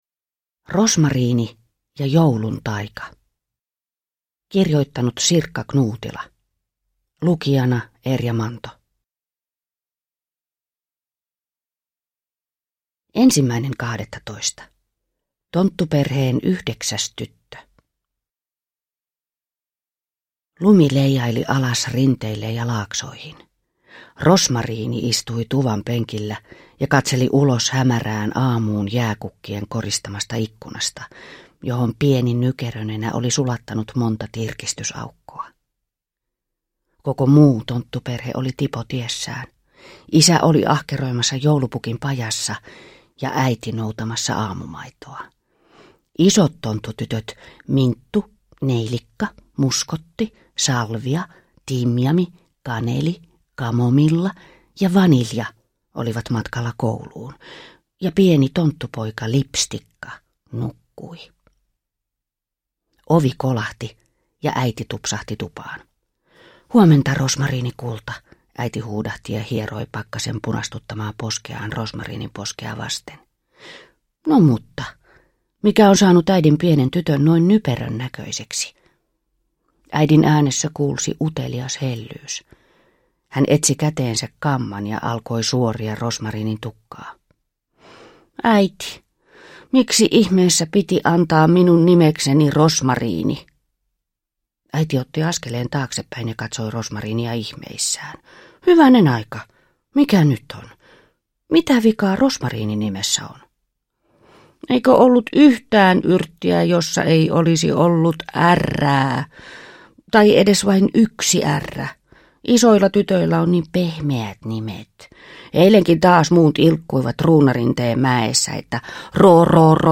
Rosmariini ja joulun taika – Ljudbok – Laddas ner